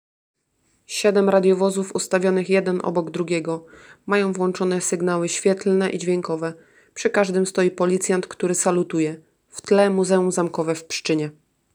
• Zdjęcie kolorowe 7 radiowozów z włączonymi sygnałami świetlnymi, w tle Muzeum Zamkowe w Pszczynie
Całość odbyła się pod Muzeum Zamkowym.